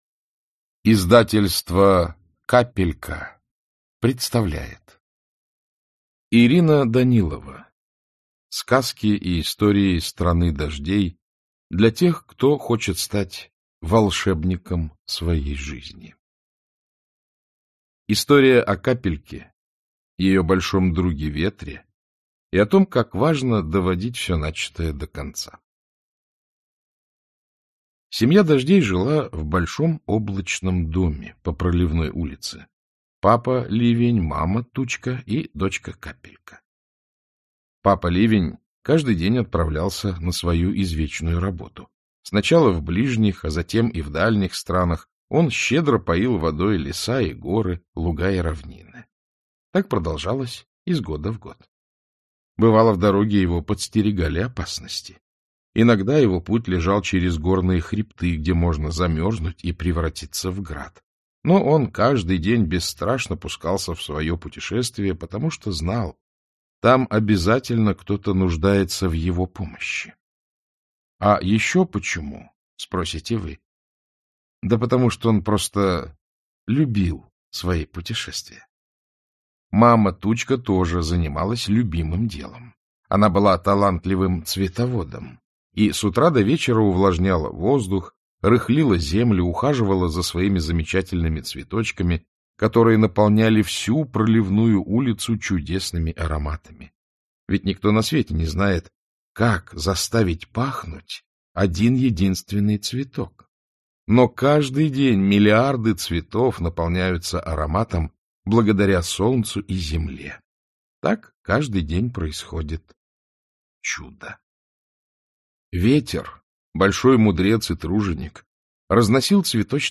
Аудиокнига История о Капельке. Как важно доводить всё начатое до конца | Библиотека аудиокниг
Как важно доводить всё начатое до конца Автор Ирина Данилова Читает аудиокнигу Александр Клюквин.